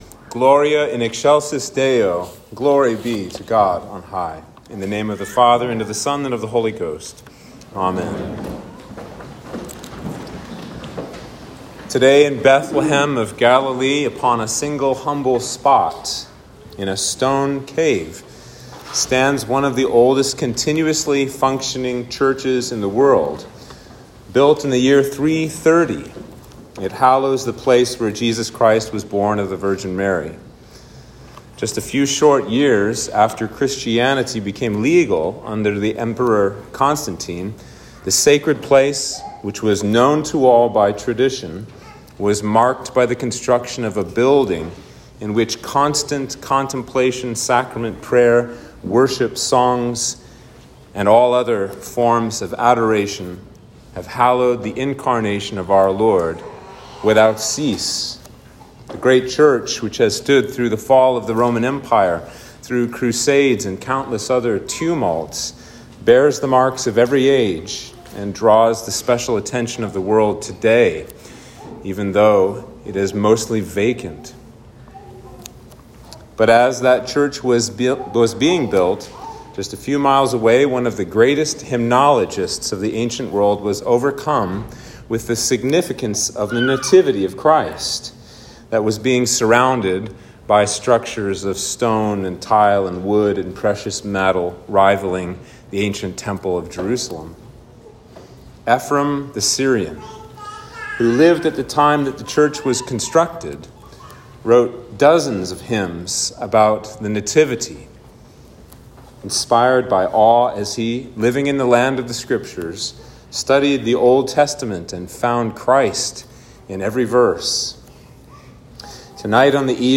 Sermon for Christmas Eve